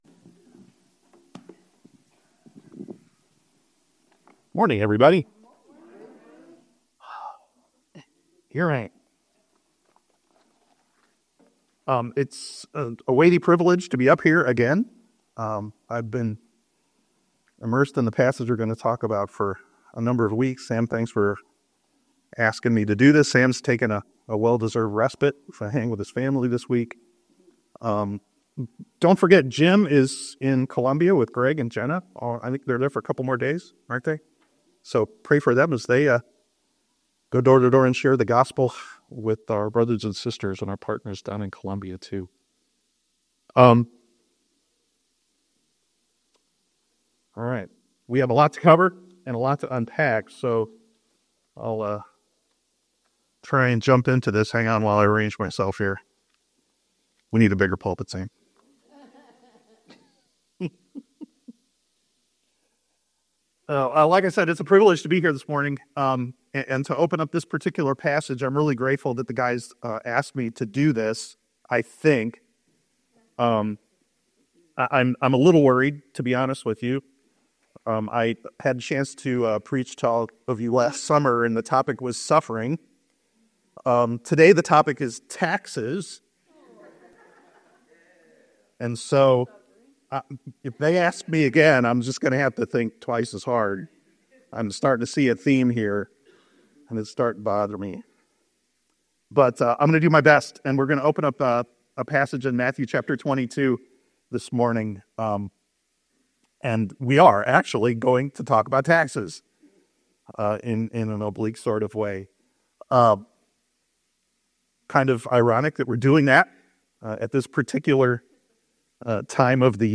In this sermon, we delve into Matthew 22:15-22, where Jesus is confronted with a challenging question about paying taxes to Caesar.